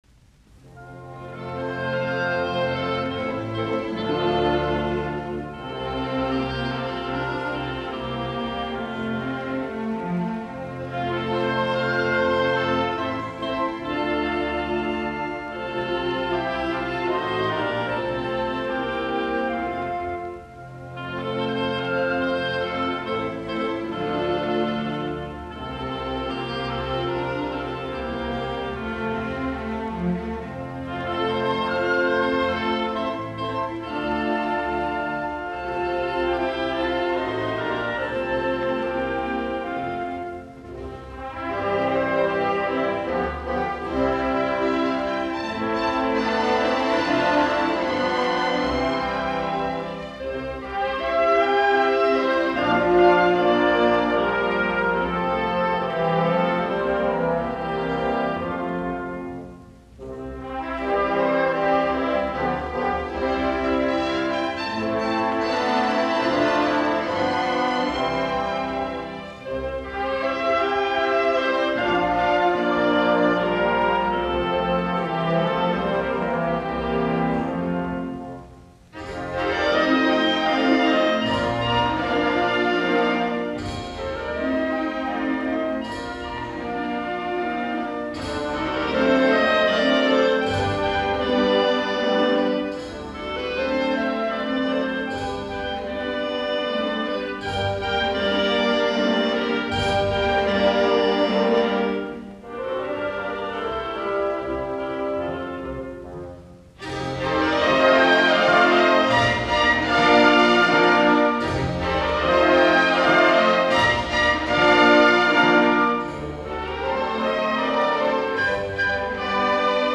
Slovanské tance, ork., op46 (B83).
Soitinnus: Ork